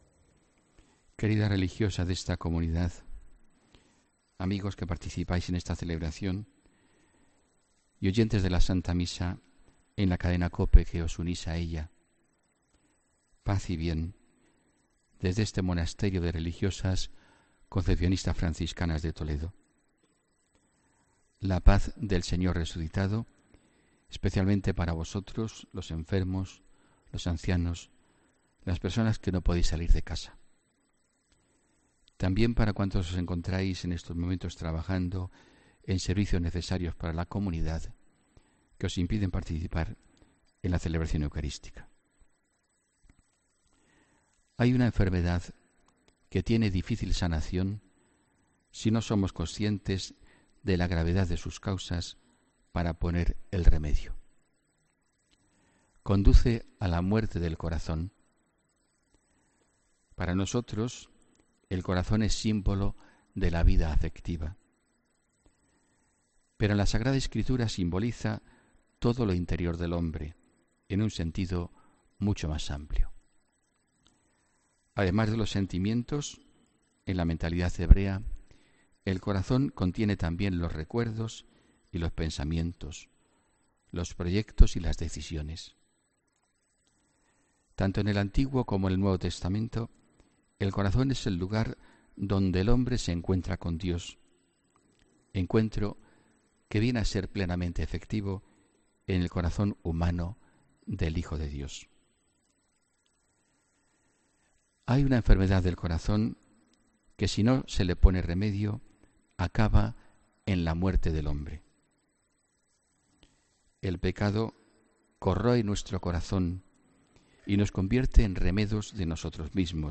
HOMILÍA 3 DICIEMBRE 2017